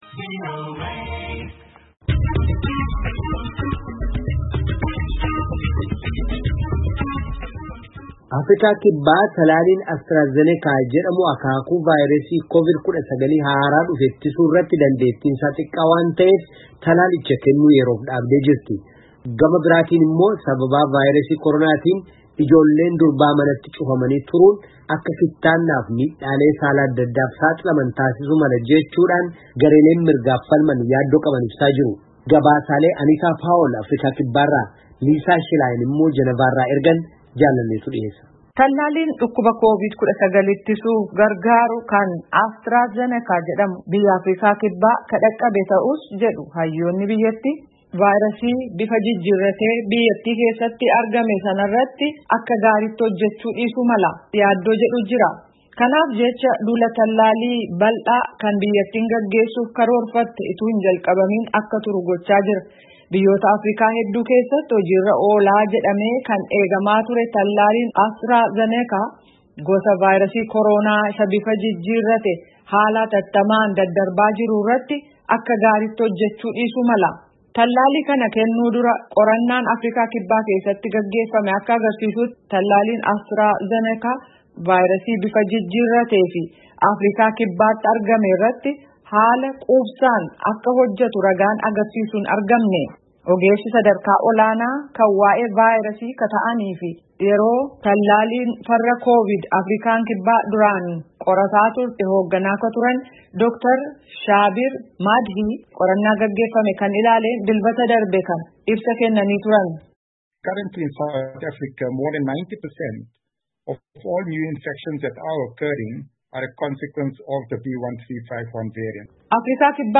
Gabaasaalee